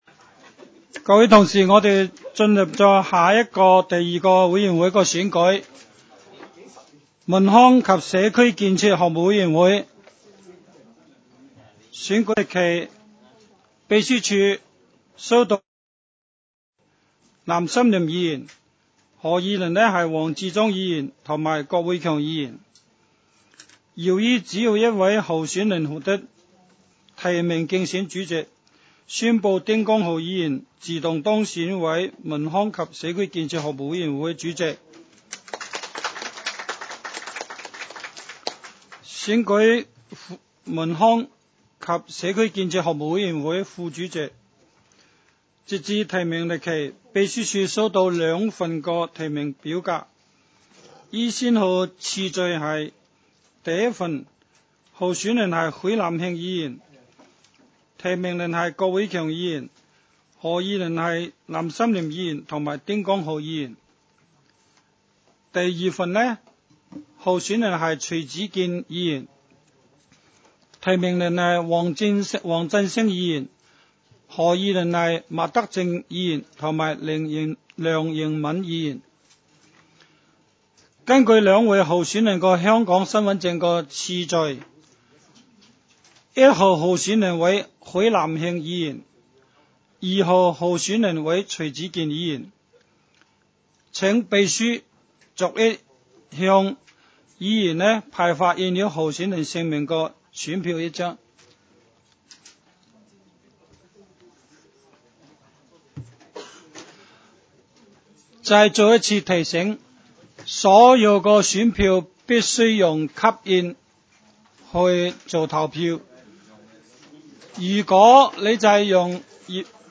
委员会会议的录音记录
文康及社区建设服务委员会第一次会议 日期: 2018-01-02 (星期二) 时间: 下午2时50分 地点: 香港西湾河太安街 29 号 东区法院大楼 11 楼东区区议会会议室 议程 讨论时间 I 推选委员会主席及副主席 0:09:29 全部展开 全部收回 议程:I 推选委员会主席及副主席 讨论时间: 0:09:29 前一页 返回页首 如欲参阅以上文件所载档案较大的附件或受版权保护的附件，请向 区议会秘书处 或有关版权持有人（按情况）查询。